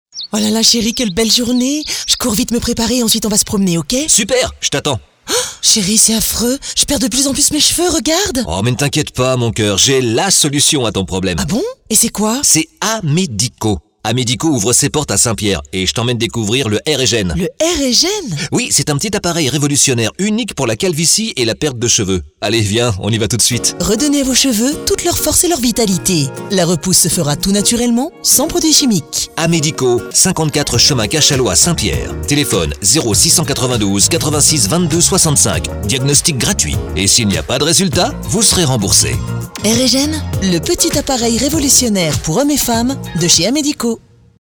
Audio Pub Radio